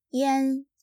「a」の読み方は、基本的に「ア」ですが、下記のピンインは「エ」と発音されます。
01-yan.mp3